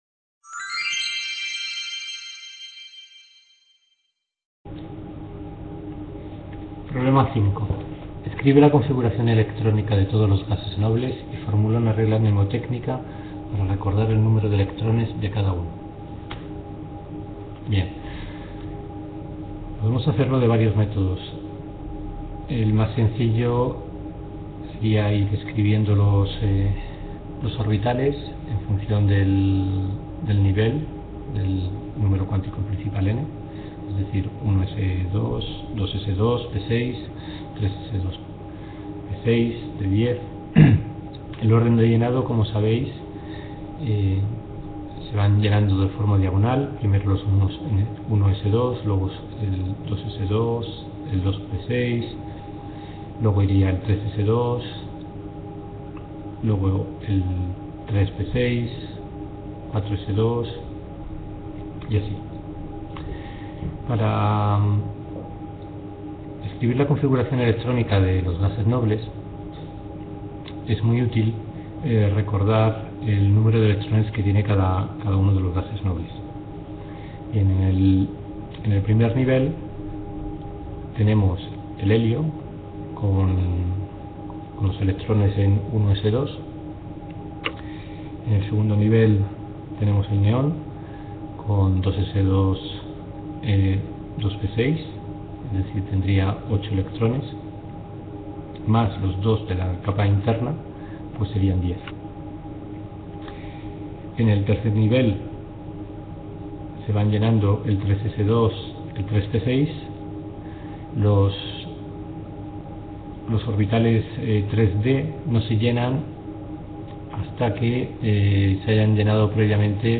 Video Clase
Tutoría (Enseñanza)